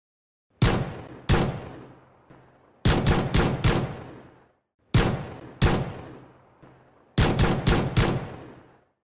Pistol Close Pubg Sound Button: Unblocked Meme Soundboard